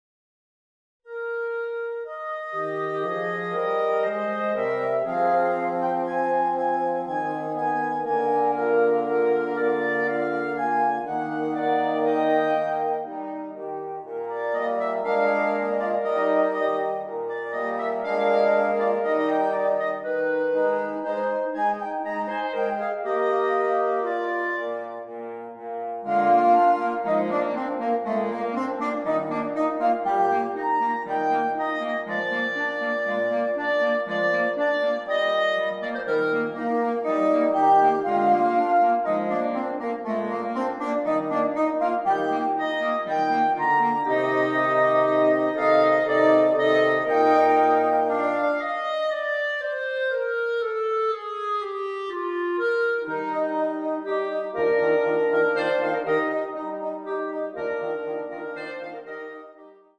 2 clarinets, 2 horns, 2 bassoons
(Audio generated by Sibelius/NotePerformer)